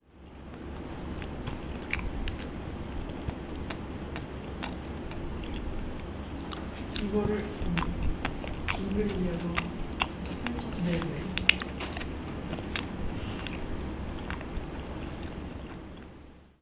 과즙팡팡_바나나먹는소리.mp3